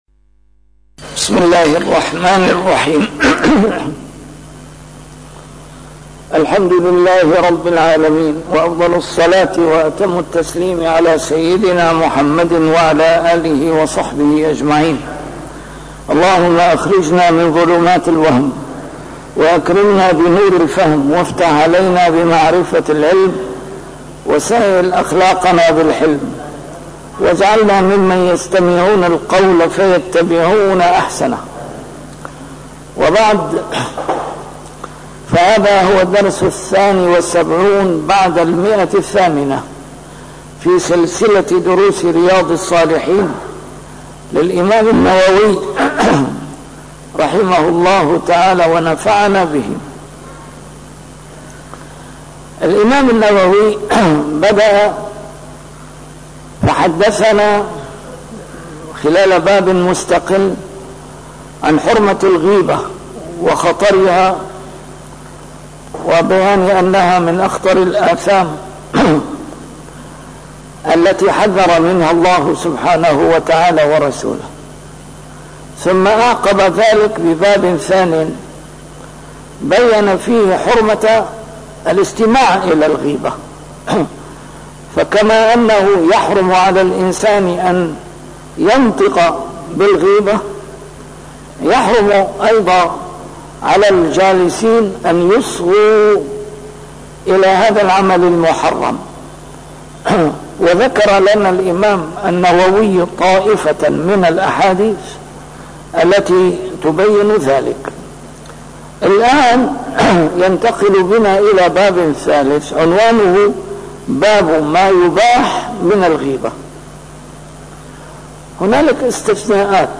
A MARTYR SCHOLAR: IMAM MUHAMMAD SAEED RAMADAN AL-BOUTI - الدروس العلمية - شرح كتاب رياض الصالحين - 872- شرح رياض الصالحين: ما يباح من الغيبة